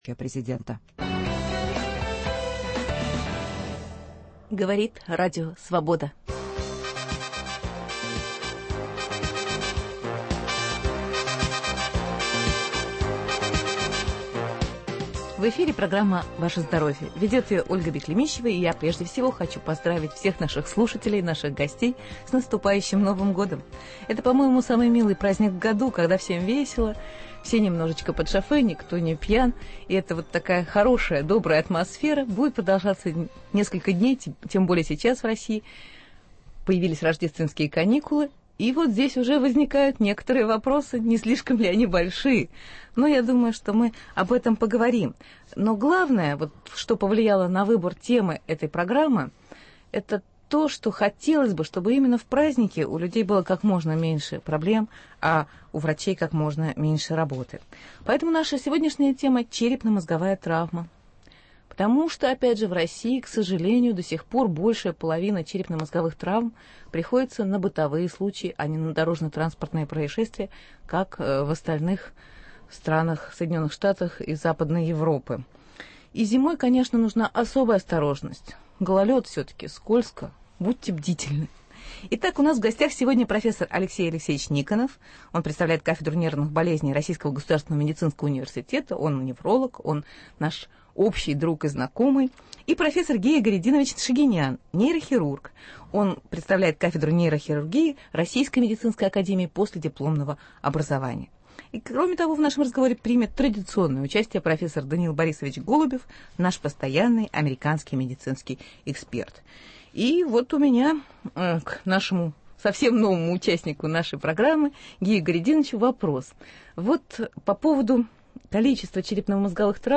Что же делать, если черепно-мозговая травма уже есть? Слушайте РС – в эфире невролог и нейрохирург.